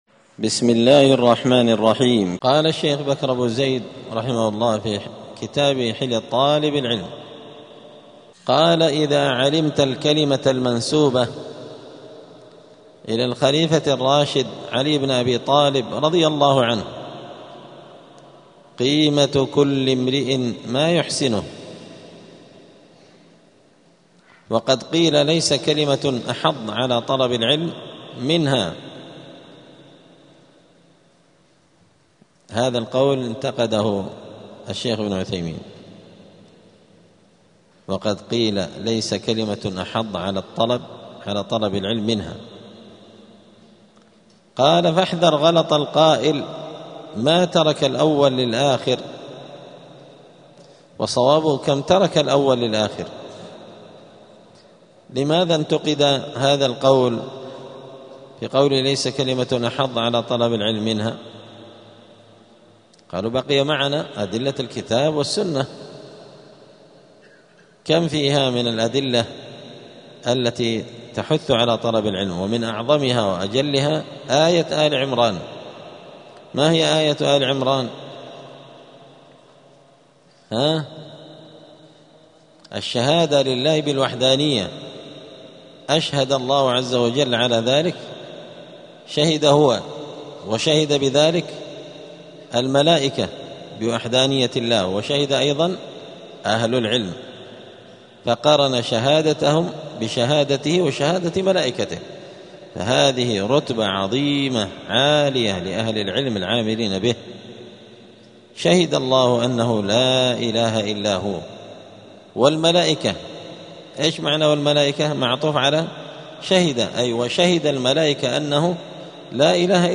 الجمعة 23 جمادى الأولى 1447 هــــ | الدروس، حلية طالب العلم، دروس الآداب | شارك بتعليقك | 7 المشاهدات